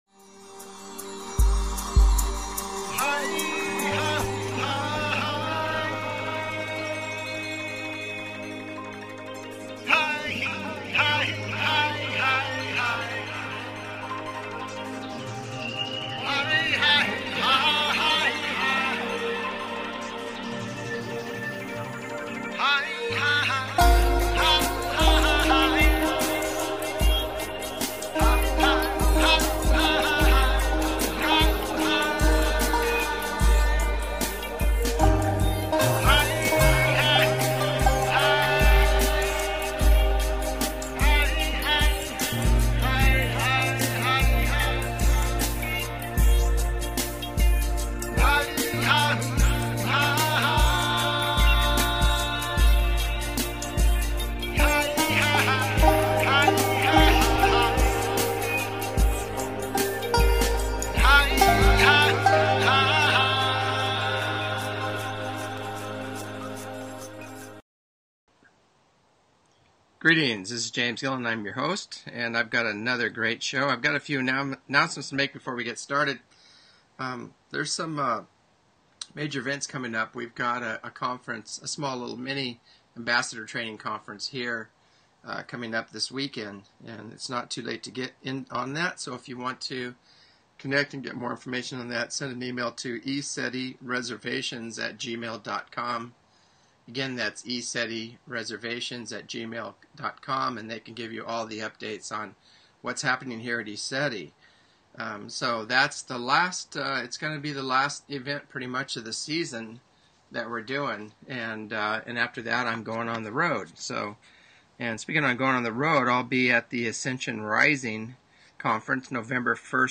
Talk Show Episode, Audio Podcast, As_You_Wish_Talk_Radio and Courtesy of BBS Radio on , show guests , about , categorized as
As you Wish Talk Radio, cutting edge authors, healers & scientists broadcasted Live from the ECETI ranch, an internationally known UFO & Paranormal hot spot.